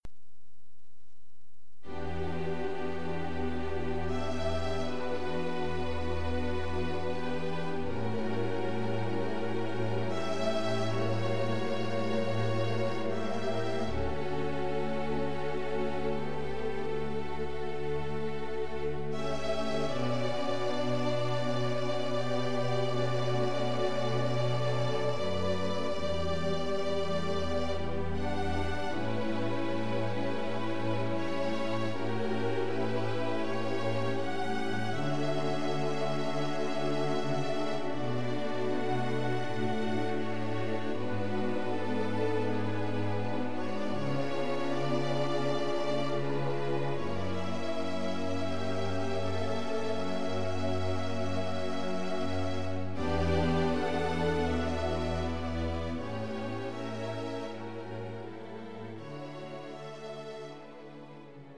A modern piece for String Ensemble
A somber work for string orchestra.